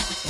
OpenHat (In The Moment).wav